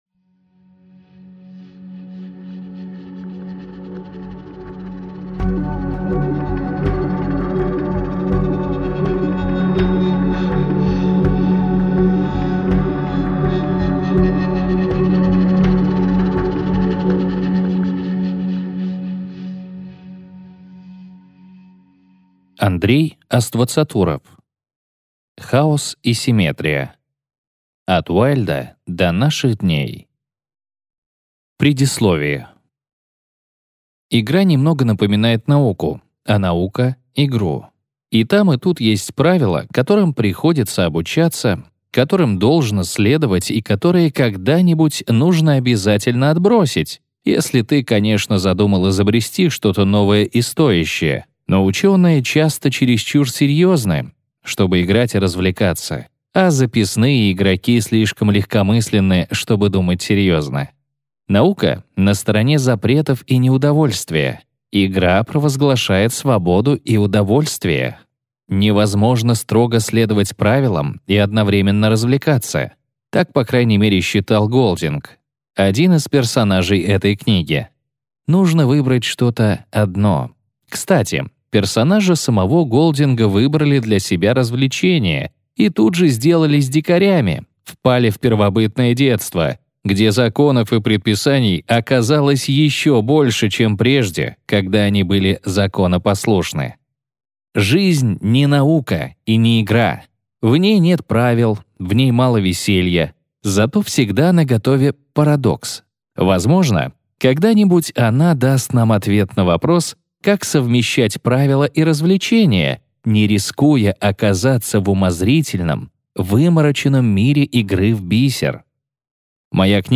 Аудиокнига Хаос и симметрия. От Уайльда до наших дней | Библиотека аудиокниг